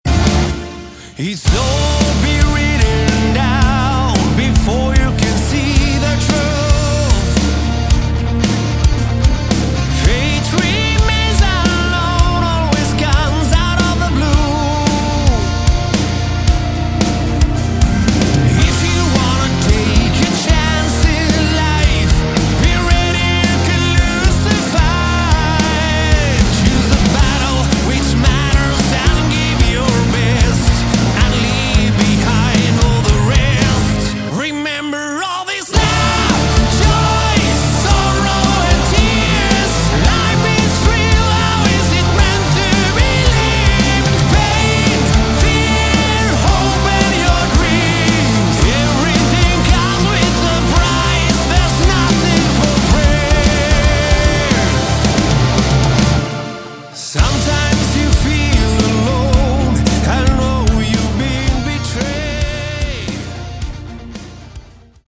Category: Hard Rock
vocals
drums
guitars
bass
keyboards